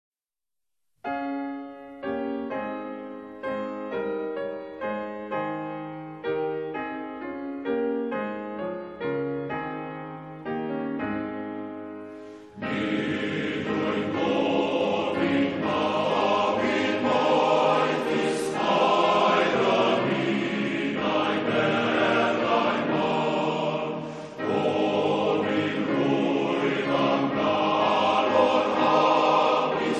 Calon Lan is a Welsh song which was written as a hymn in the 1890s.
Seiriol Choir